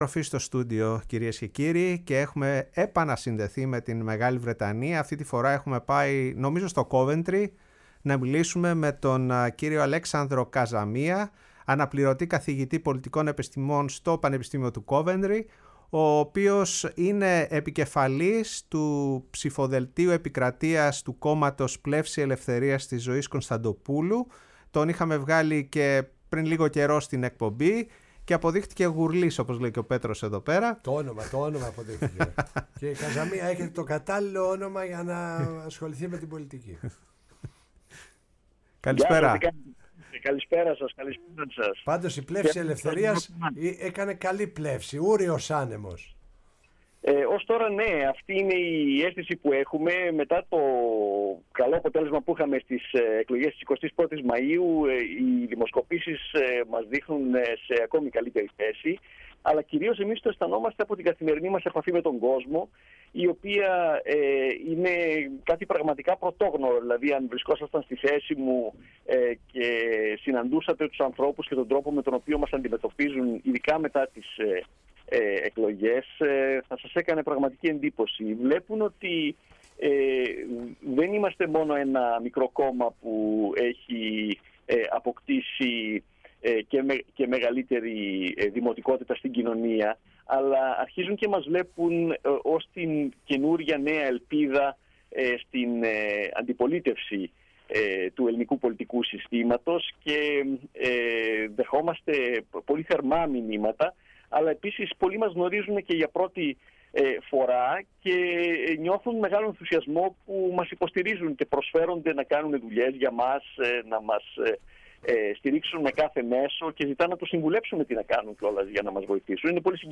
Συνεντεύξεις